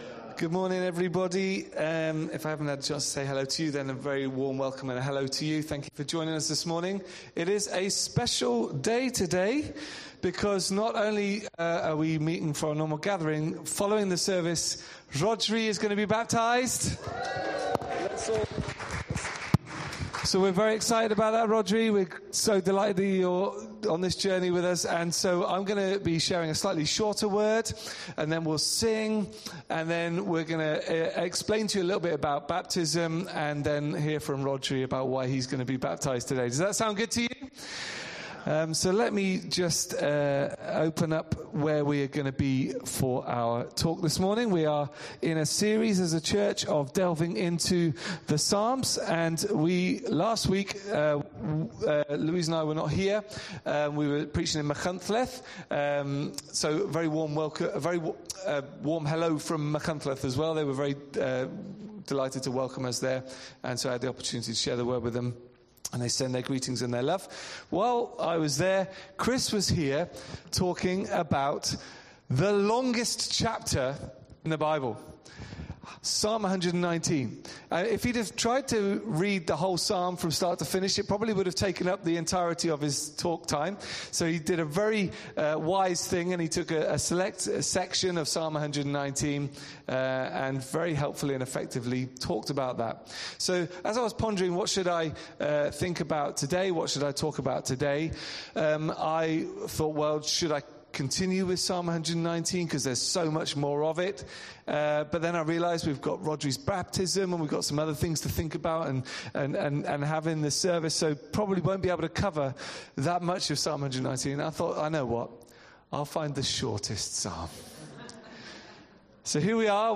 All our talks & sermons are available on Apple's Podcast channel, Spotify and most major podcast apps.